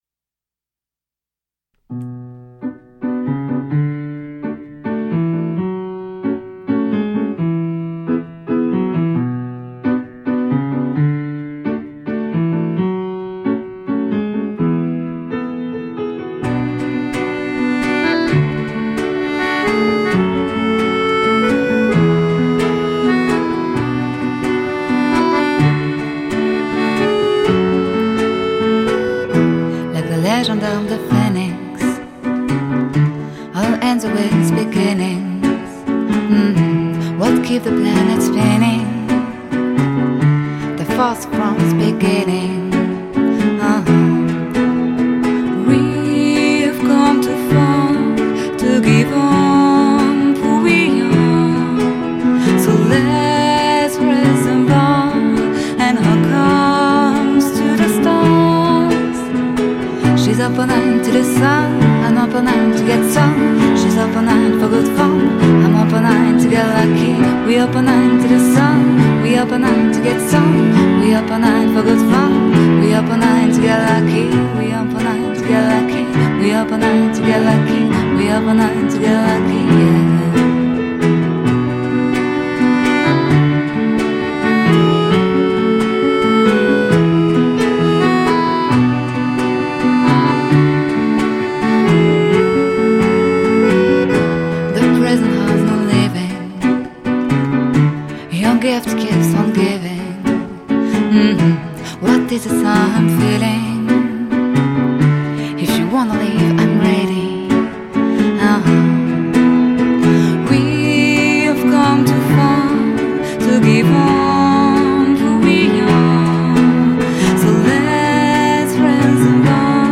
Жанр: instrumental, chanson à texte.
Фортепиано, гитара, вокал, аккордеон, скрипка.